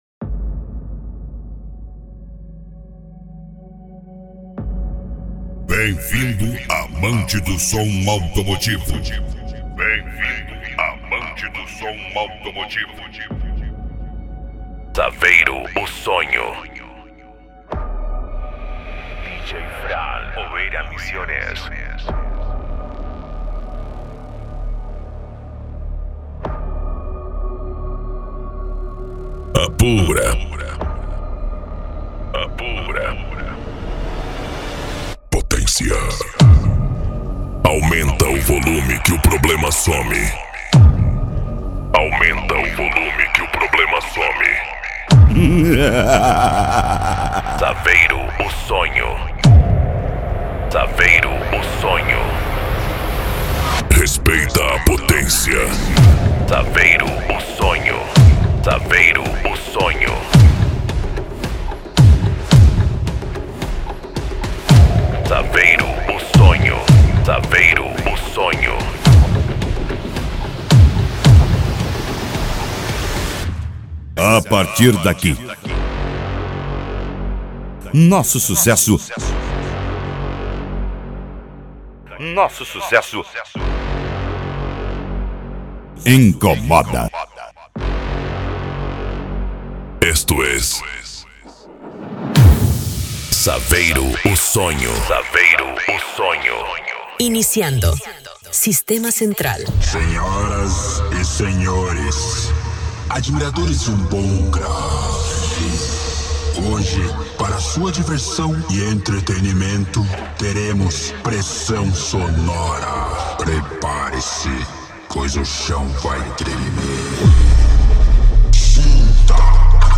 Bass
Funk
SERTANEJO